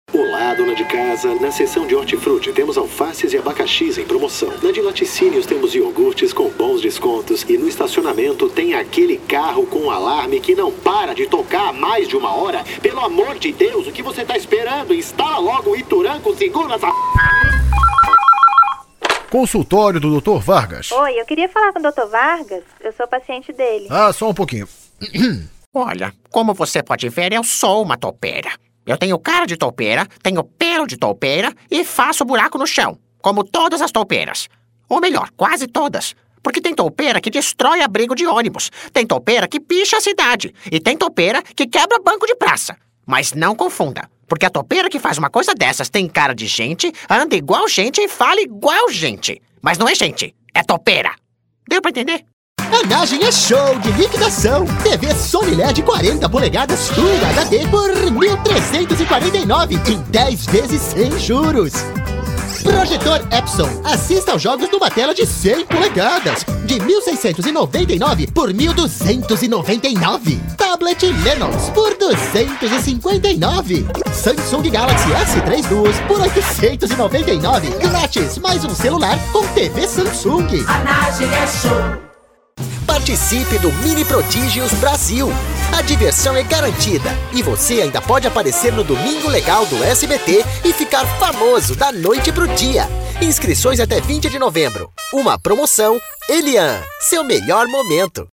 Voice over in portuguese, Institutional or Conversational
Sprechprobe: Sonstiges (Muttersprache):
Voice over in Brazilian Portuguese